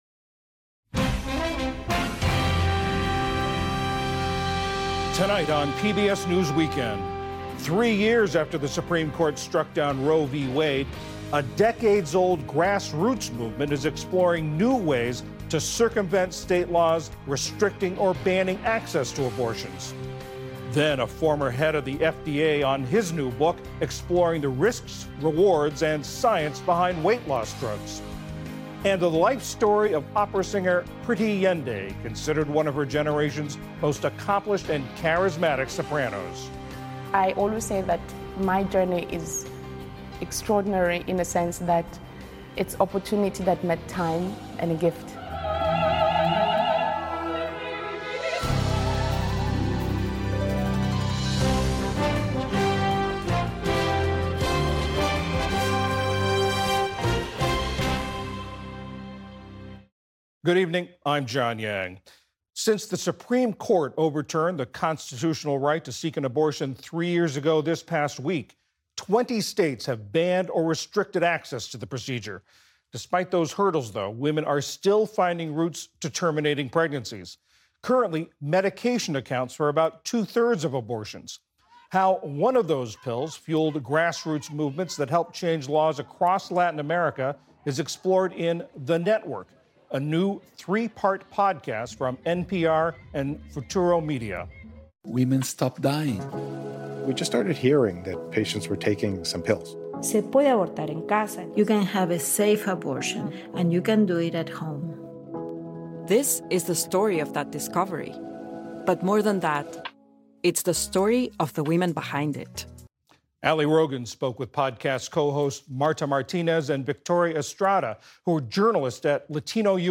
News, Daily News